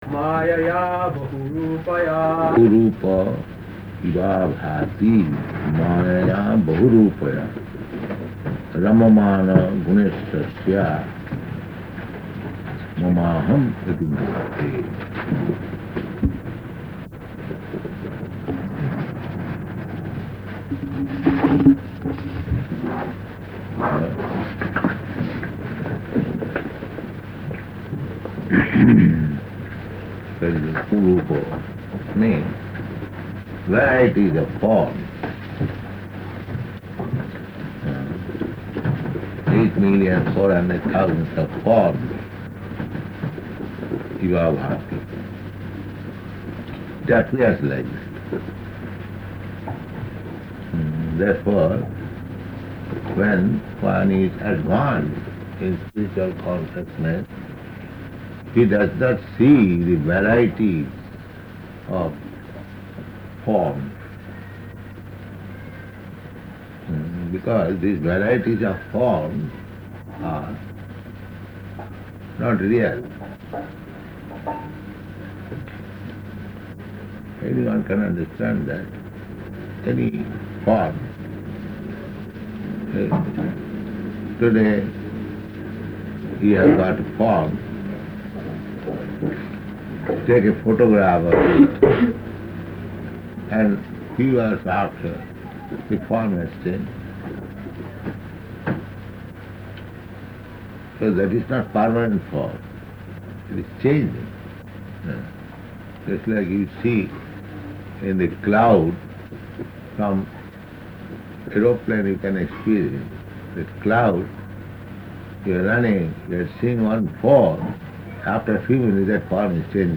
Location: Tokyo